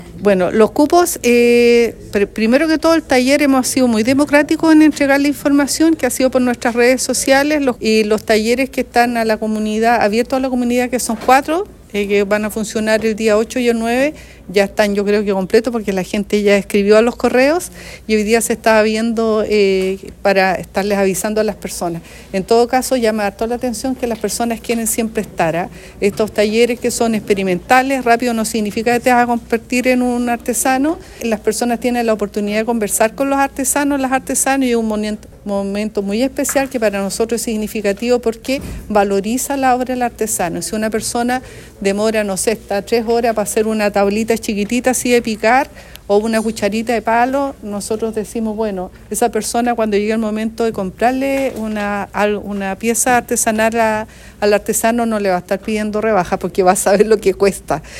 Este lunes, en la sala de sesiones del municipio de Osorno, se realizó el lanzamiento oficial de “Guardianes de Oficios”, una iniciativa que tendrá lugar los días 7, 8 y 9 de noviembre en el Centro Cultural de Osorno.